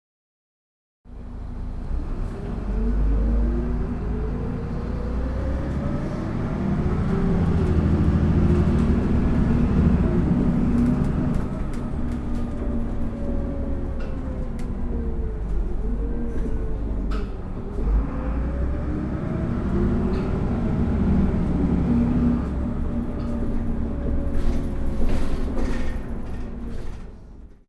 機械のモーター音やアナウンス、発車の合図......。地下鉄、都電荒川線、都営バスのそれぞれの場所でしか聞くことができない音を収録しました。
第3回都営バス「車両走行音」